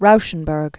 (roushən-bûrg), Robert 1925-2008.